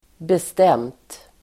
Uttal: [best'em:t]